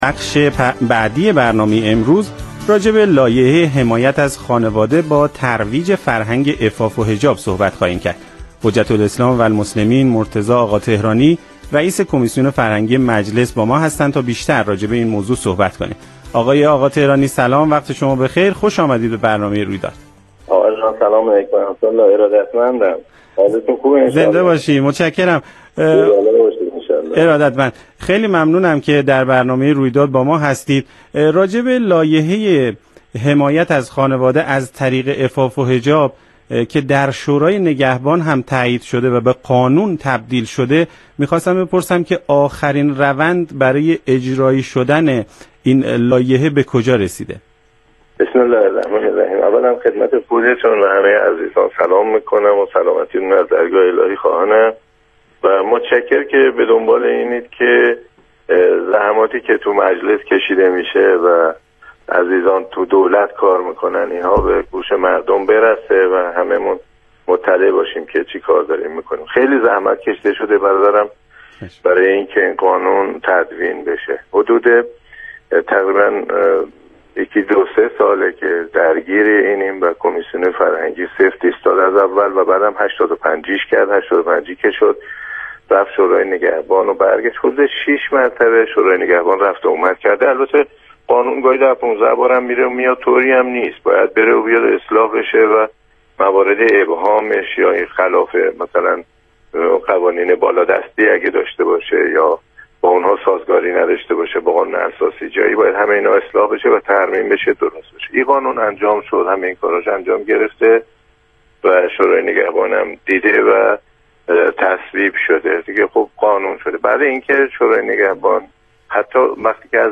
آخرین روند لایحه حمایت از خانواده در گفتگو با رادیو